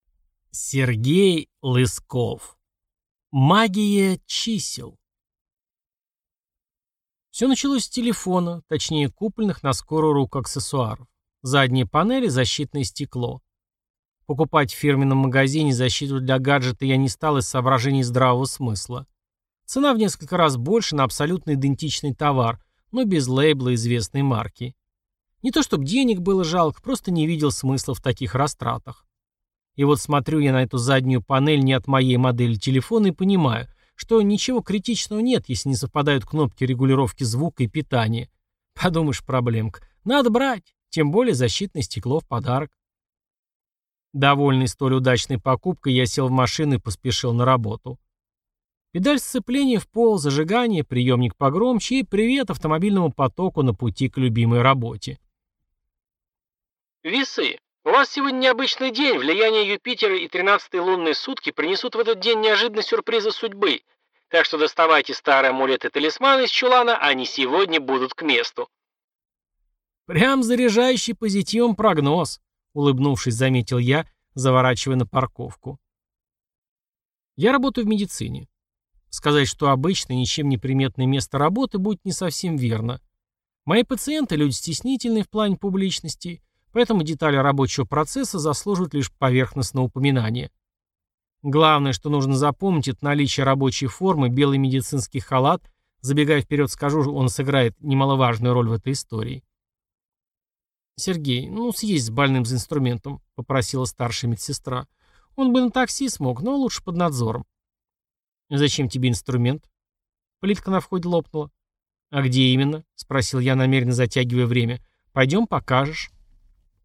Аудиокнига Магия чисел | Библиотека аудиокниг